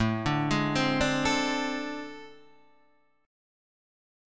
AmM7bb5 chord {5 5 6 5 3 5} chord